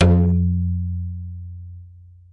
描述：来自带有2个橡皮筋和2个弹簧的接触式麦克风仪器。
Tag: 模拟 接触 橡胶带 弹簧 噪声